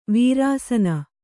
♪ vīrāsana